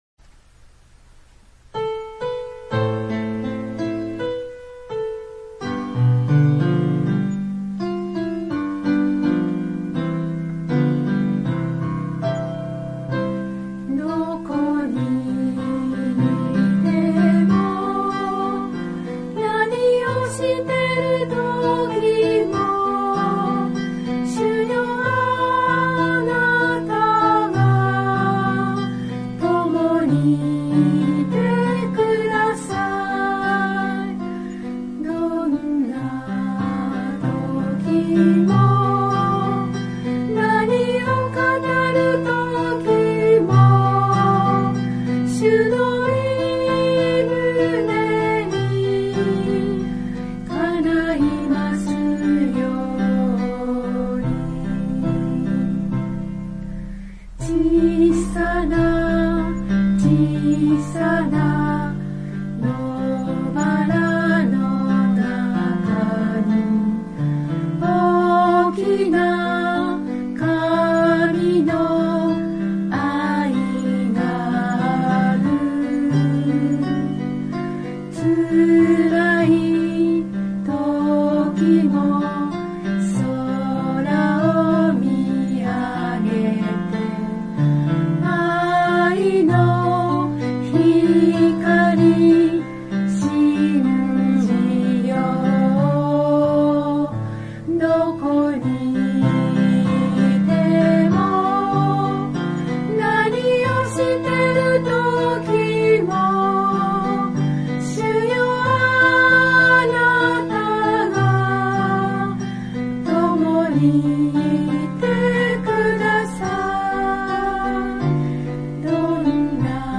God bless you）より 唄